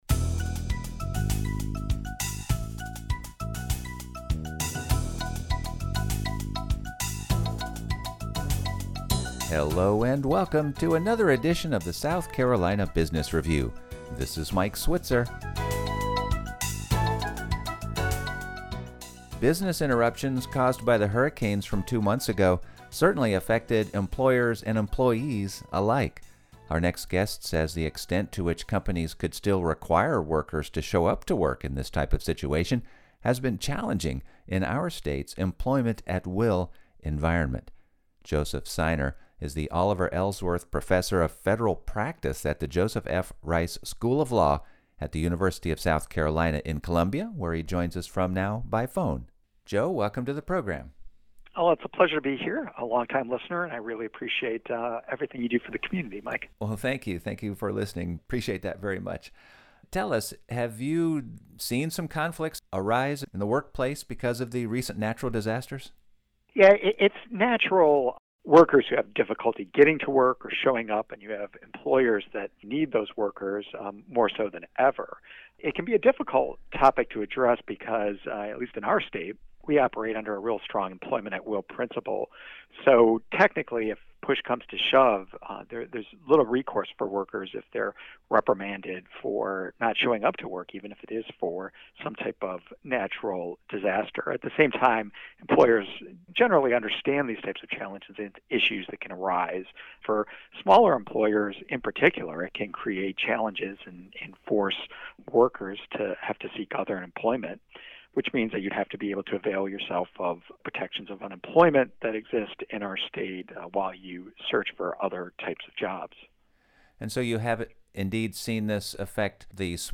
focuses on news from South Carolina's business community with interviews of many small business owners and business leaders from around the state.